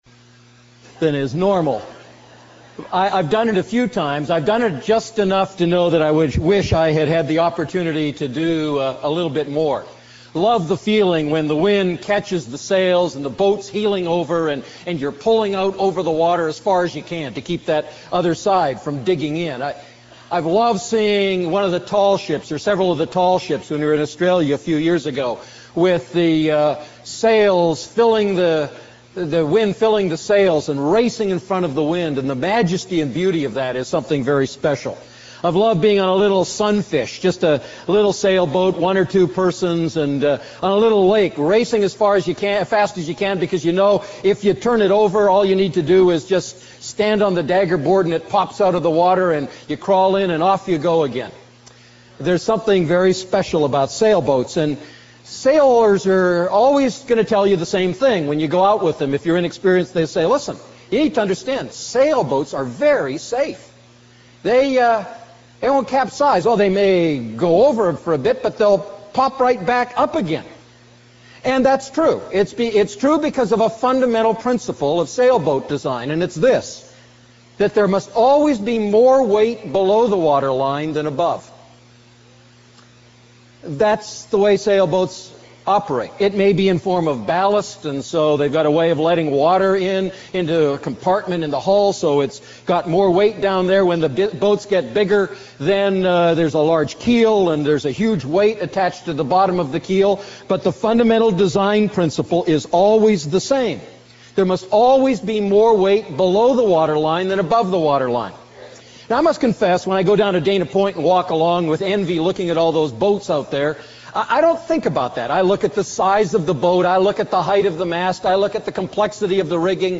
A message from the series "Living Inside Out."